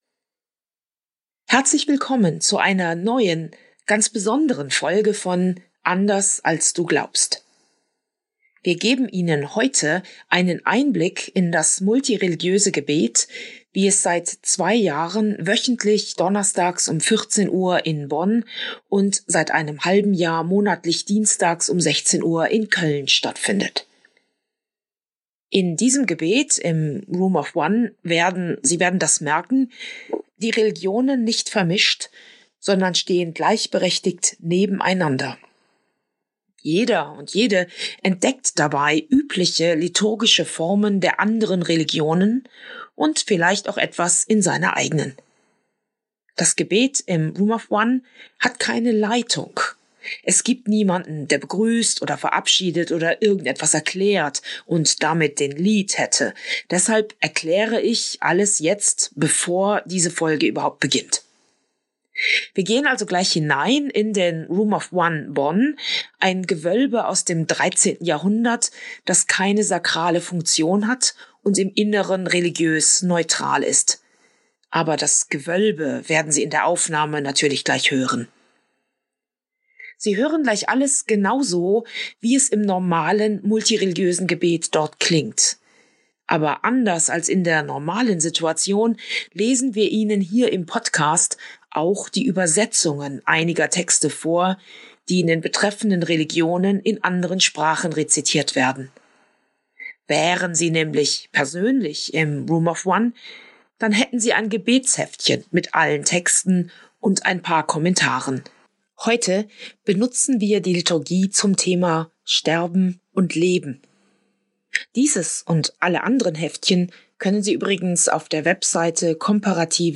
Beschreibung vor 1 Woche In dieser Folge nehmen wir Sie hinein in das Multirelilgiöse Nachmittagsgebet in den Room of One Bonn. Sie erleben eine liturgische Begegnung von Judentum, Christentum und Islam. Unterteilt werden die liturgischen Einheiten durch Komposititionen des interreligiösen Chorprojekts "Trimum", die zum Teil für den Room of One geschrieben wurden.